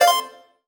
collect_coin_01.wav